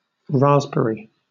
Ääntäminen
Southern England
IPA : /ˈɹɑːzb(ə)ɹi/